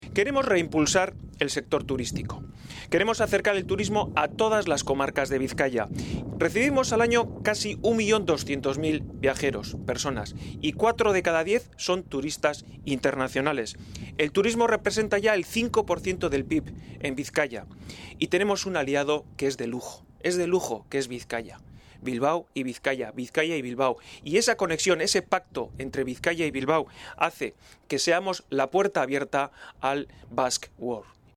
El candidato de EAJ-PNV a diputado general de Bizkaia se ha referido al turismo como una oportunidad para Bizkaia. Y lo ha hecho en el muelle de cruceros de Getxo agradeciendo a José Luis Bilbao y a su equipo el trabajo realizado para dotar a este Territorio de unas infraestructuras de primer nivel.